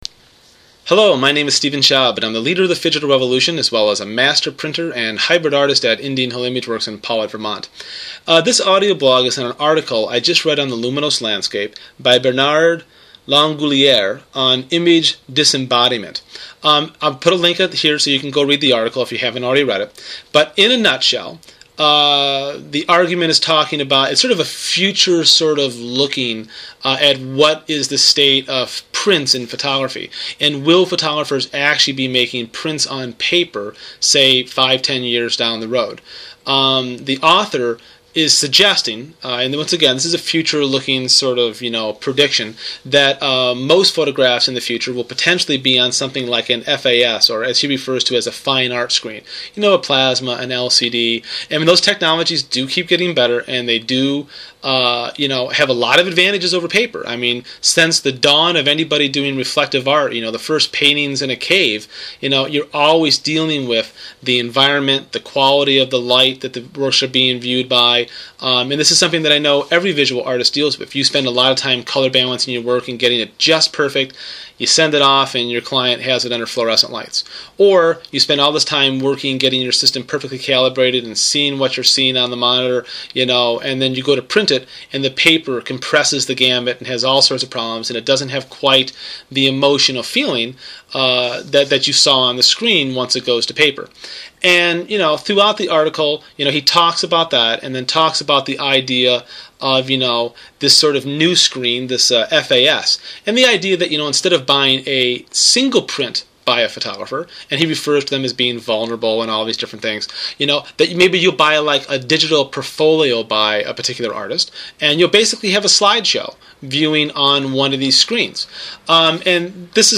In this audioblog I present a philosophy of scanning film based on the understanding that in the end photographers make prints and our entire process from film capture to scan to Photoshop to printer is all based on making prints. I outline in detail techniques and settings which will apply to most scanners and will help you get the most of your film and scanner combination.